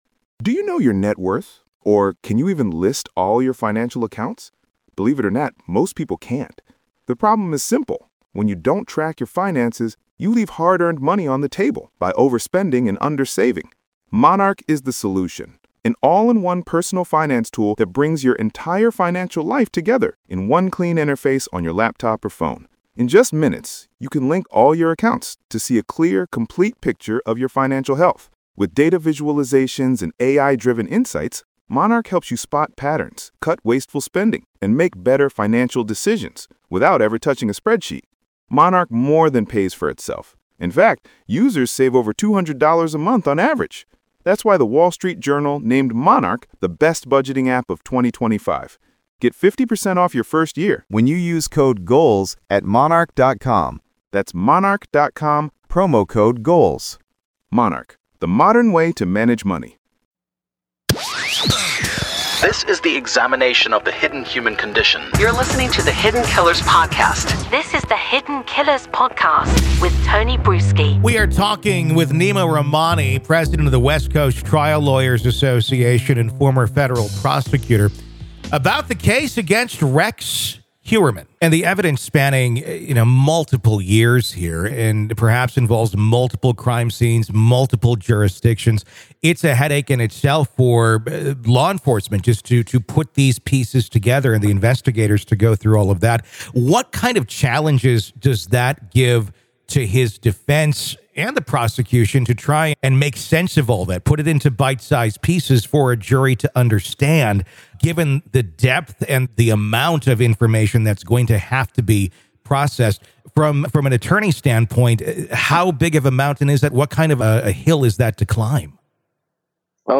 True Crime Today | Daily True Crime News & Interviews / Is Heuermann Going Away For Life No Matter What?